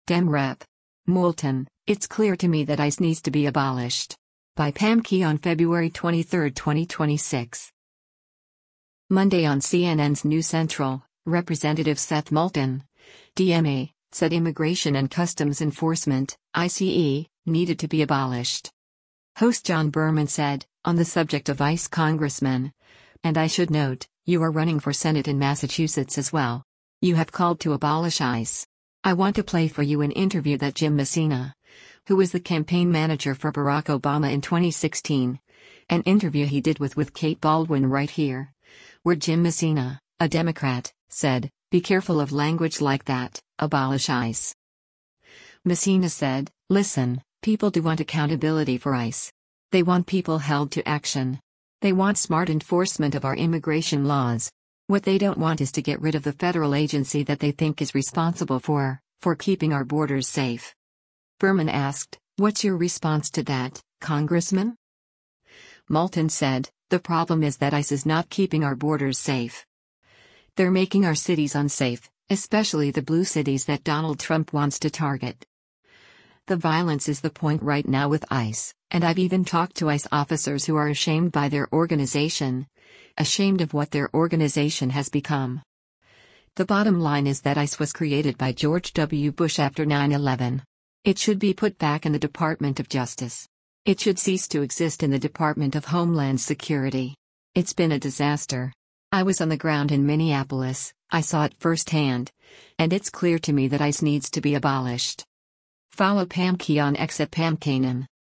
Host John Berman said, “On the subject of ICE Congressman, and I should note, you are running for Senate in Massachusetts as well.